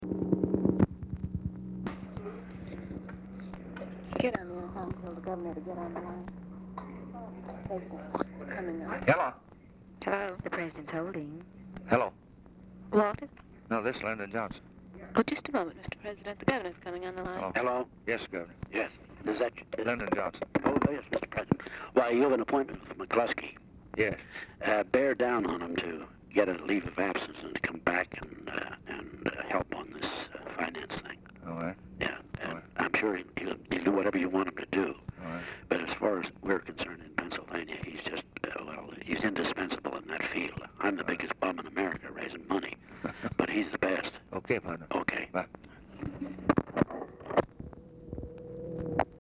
Telephone conversation # 565, sound recording, LBJ and DAVID LAWRENCE, 12/19/1963, 10:15AM | Discover LBJ
Format Dictation belt
Specific Item Type Telephone conversation Subject Diplomacy Elections National Politics Western Europe